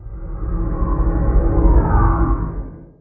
elder_idle2.ogg